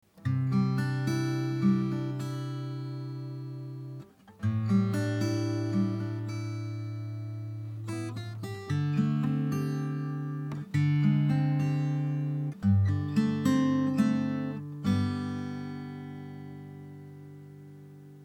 M3录吉他（不能右键另存为，必须点击后在线收听）
结论：吉他录制的还是比较通透的，典型的小振膜声音，比NT3略软一点点。